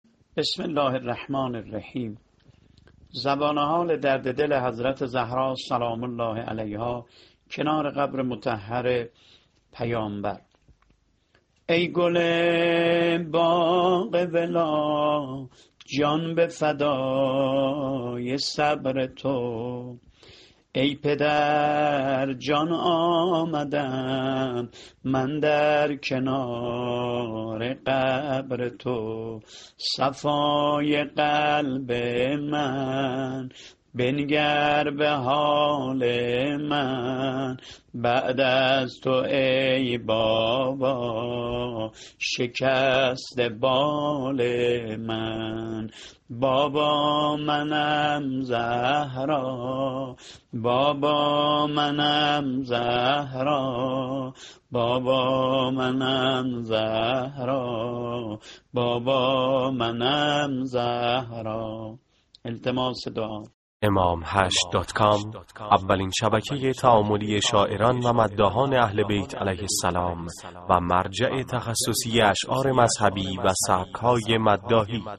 متن شعر و نوحه درددل کنارقبر پیامبر(ص) فاطمیه 98 -(ای گل باغ ولا جان بفدای صبر تو)